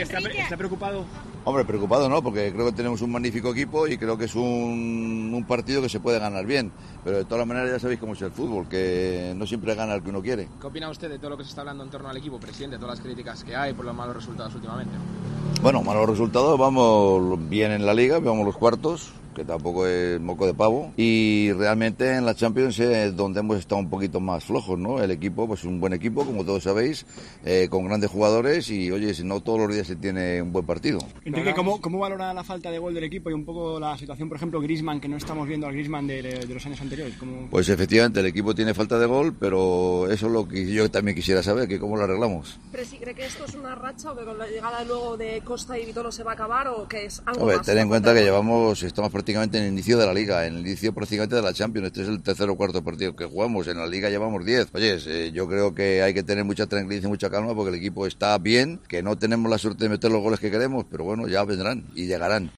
El Presidente del Atlético de Madrid atendió a los medios antes de la comida de directivas en la previa del encuentro frente al Qarabag: "Preocupado no, tenemos un magnífico equipo. Vamos bien en LaLiga, cuartos, y en la Champions sí que hemos estado más flojos. El equipo tiene falta de gol, pero eso también quiero saber como se soluciona. Hay que tener tranquilidad, ya vendrán los goles".